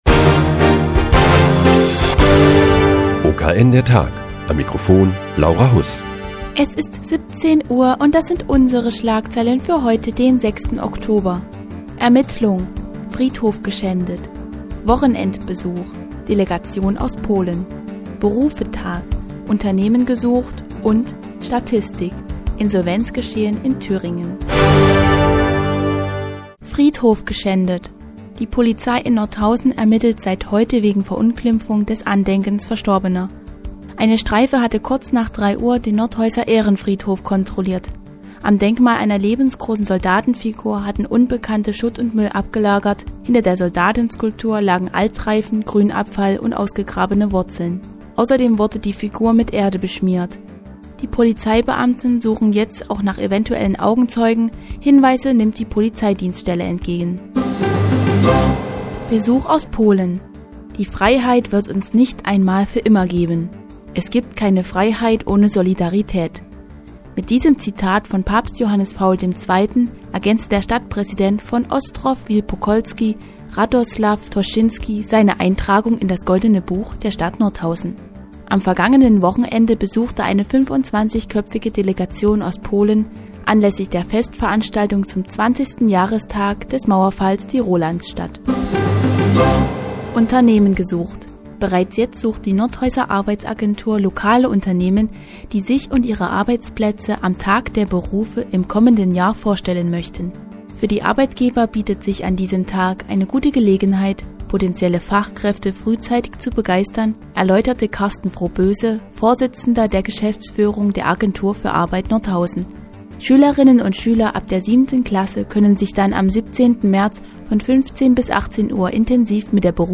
Die tägliche Nachrichtensendung des OKN ist nun auch in der nnz zu hören. Heute geht es um einen Besuch aus Polen und Unternehmensinsolvenzen in Thüringen.